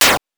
8 bits Elements / hits
hit_3.wav